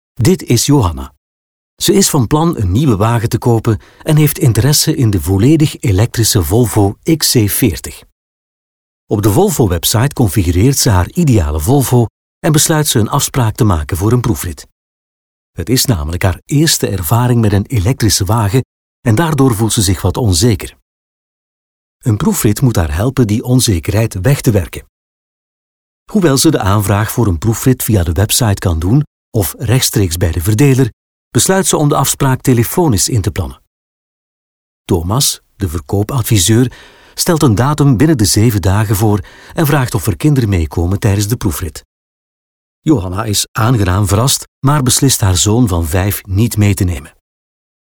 Male
Flemish (Native)
Corporate, Energetic, Engaging, Friendly, Natural, Smooth
Microphone: Neumann U89 + Brauner Phantom C + Sennheiser MKH 416